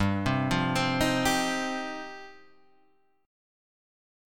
G7sus4#5 Chord